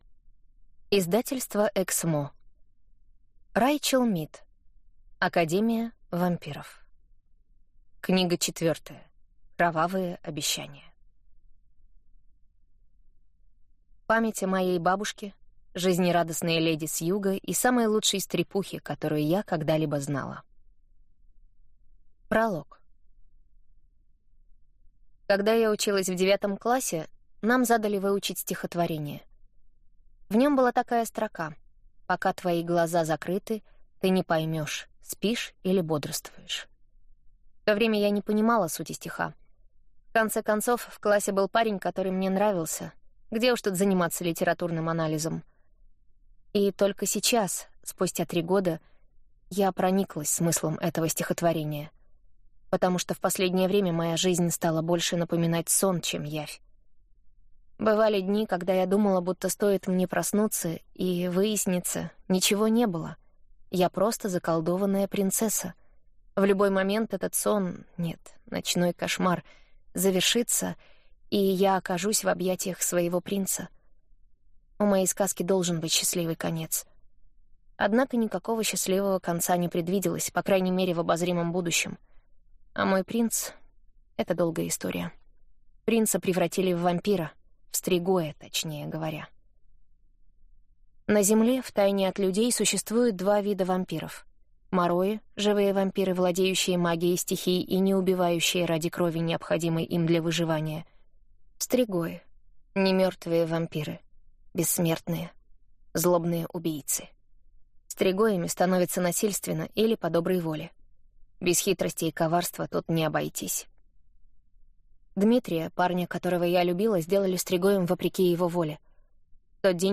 Аудиокнига Кровавые обещания | Библиотека аудиокниг